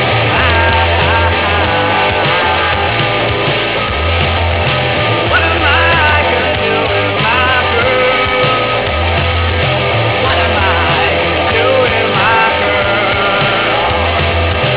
vocals, guitar
bass, vocals